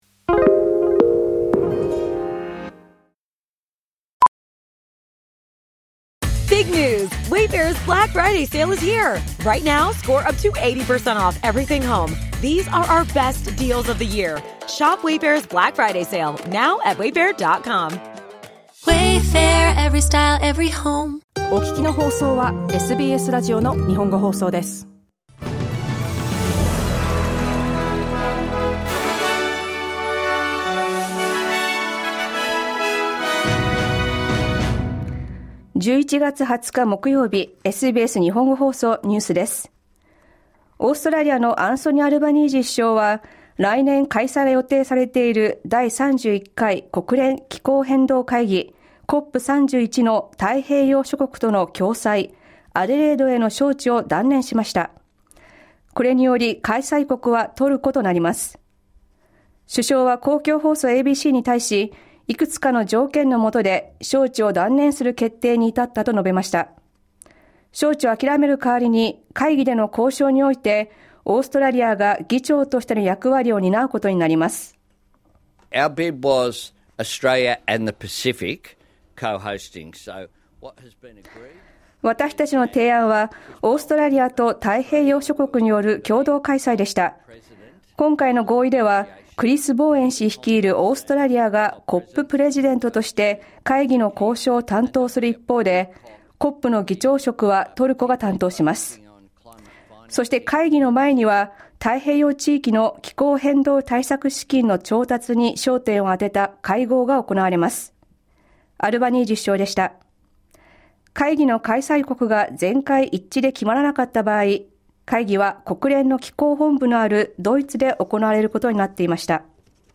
SBS日本語放送ニュース11月20日木曜日